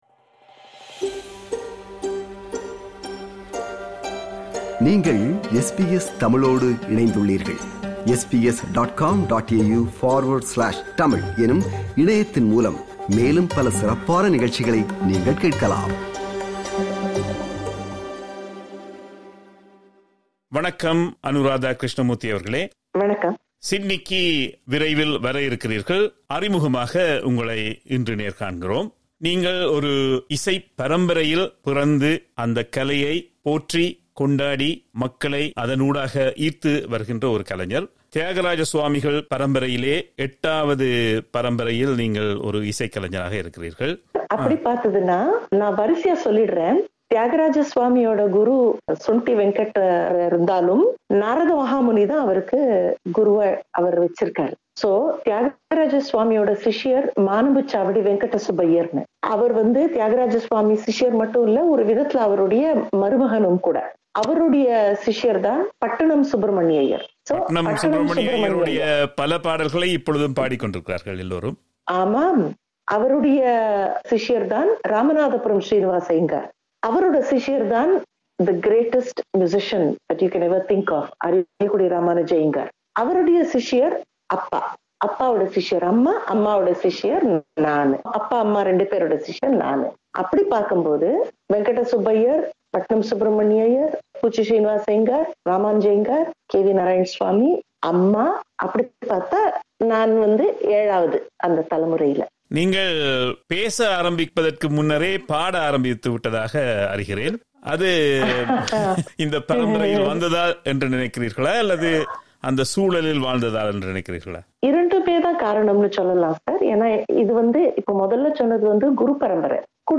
This is the first of the two-part interview.